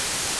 I_Static.wav